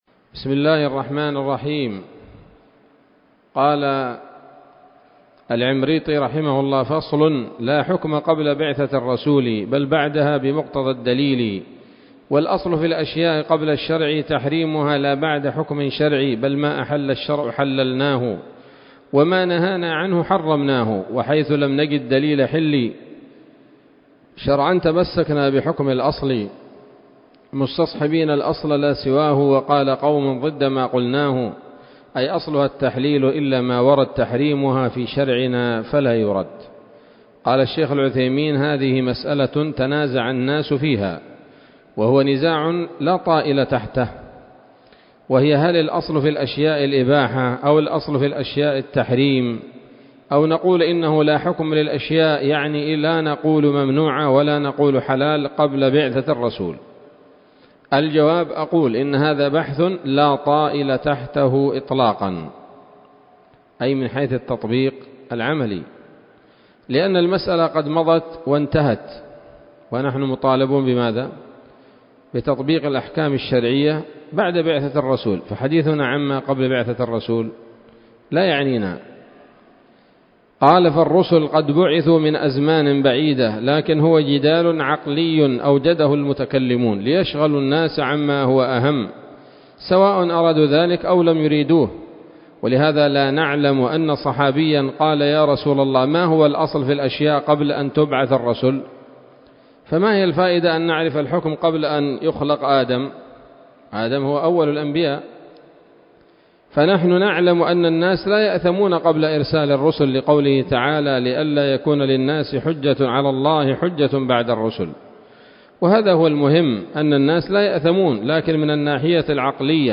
الدرس الرابع والستون من شرح نظم الورقات للعلامة العثيمين رحمه الله تعالى